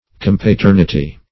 Search Result for " compaternity" : The Collaborative International Dictionary of English v.0.48: Compaternity \Com`pa*ter"ni*ty\, n. [LL. compaternitas, fr. compater godfather; com- + pater father.]